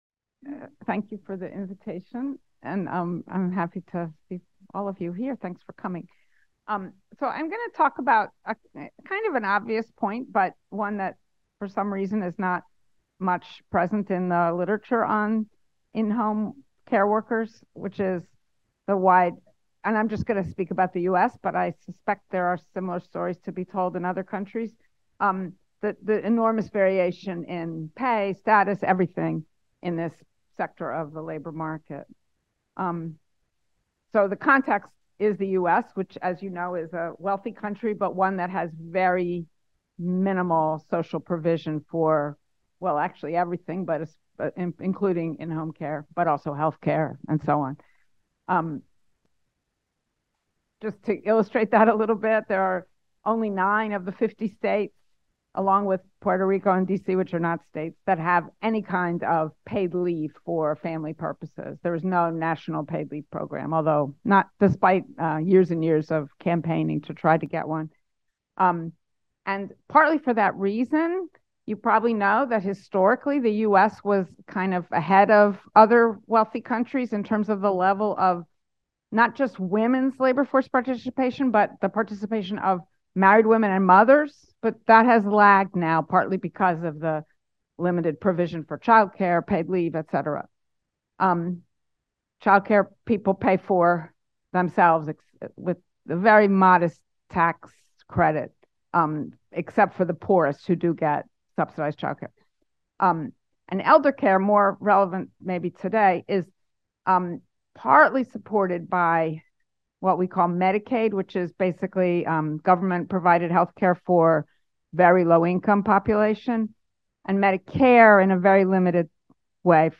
Ce colloque met en lumière les expériences vécues des travailleuses du care dans les hôpitaux, maisons de retraite, ou à domicile mais aussi d’interroger les réponses des États et autres acteurs sociaux, en identifiant les réactions communes et les contrastes parfois saisissants entre les stratégies à l’œuvre. Ce colloque réunit des spécialistes en sciences sociales de différentes disciplines (sociologie, géographie, psychologie…) qui fournissent des éclairages complémentaires sur cette période marquée par de fortes inégalités sociales.